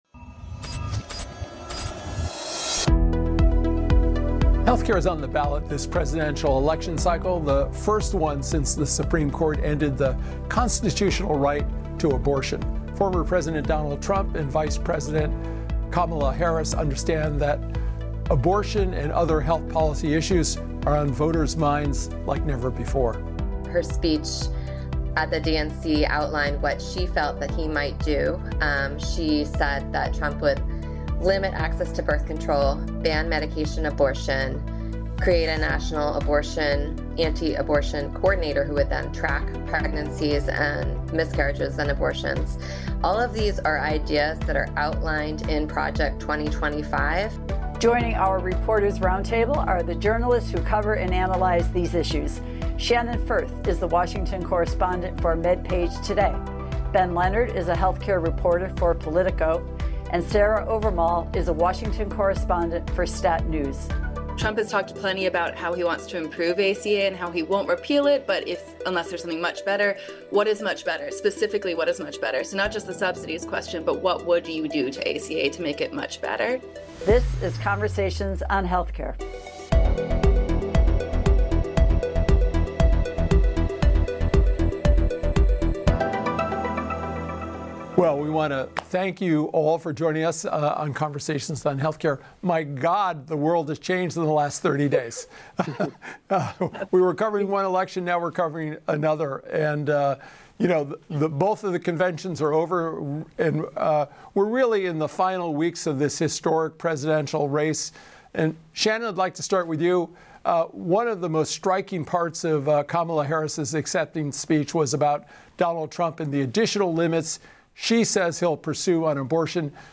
Trump, Harris on Abortion: Reporters’ Roundtable Examines Their Records